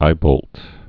(ībōlt)